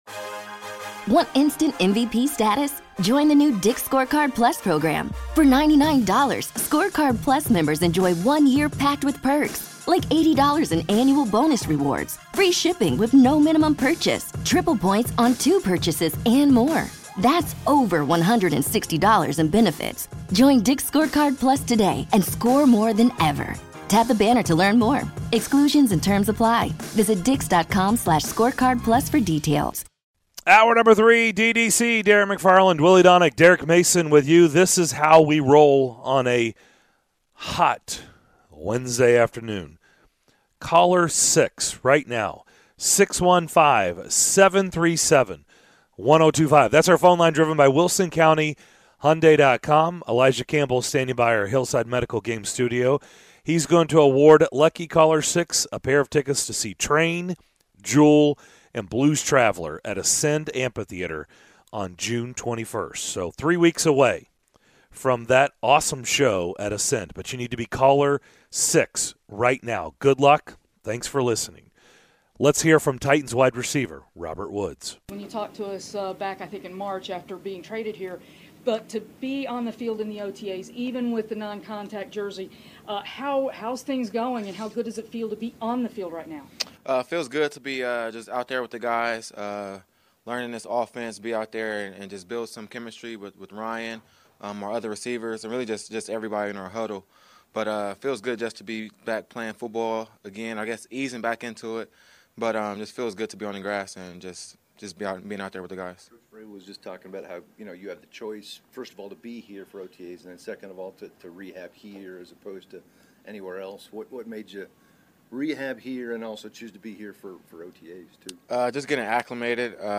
In the third hour of the show: the guys listen and react to Robert Woods' press conference at Titans OTAs, break down the potential issues with the team's receiver group and more!